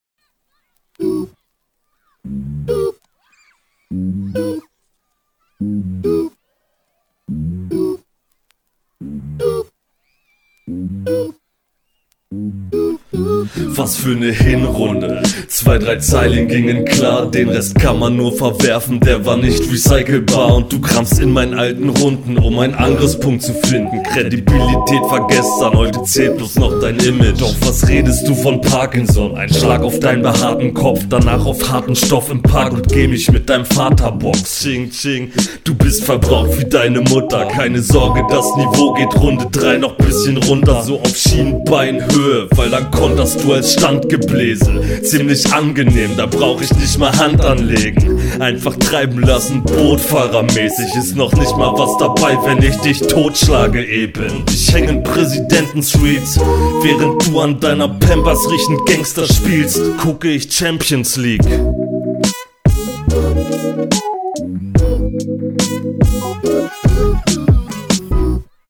stimmeinsatz klingt etwas ungut gepresst. flowst außerdem etwas langweiliger als dein gegner auf dem beat.